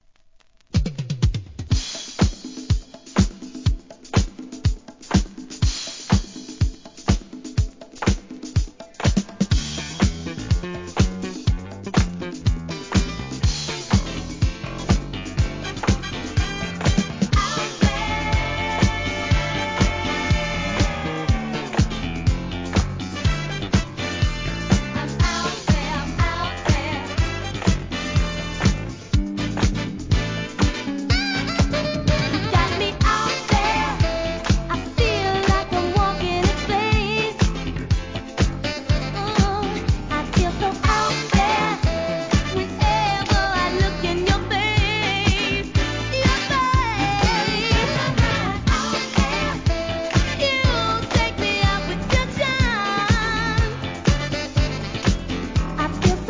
SOUL/FUNK/etc...
実力派のDISCOナンバー!!